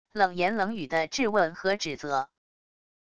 冷言冷语地质问和指责wav音频